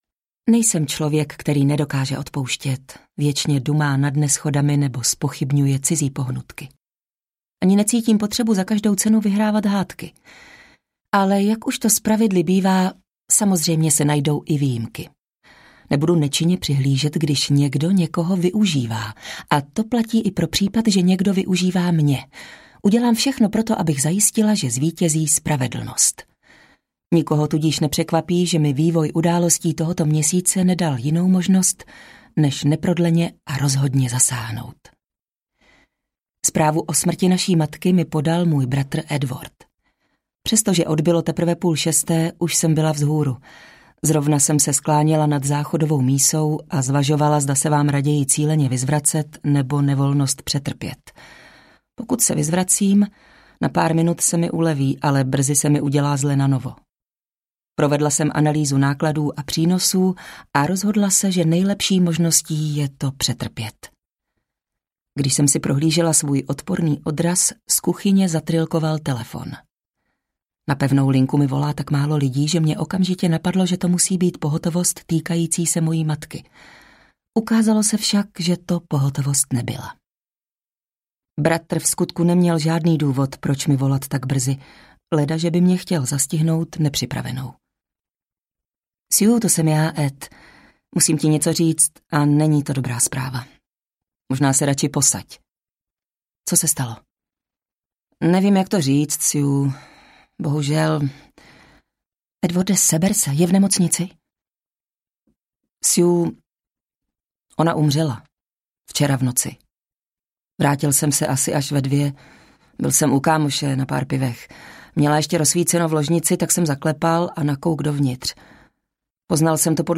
Kaktus audiokniha
Ukázka z knihy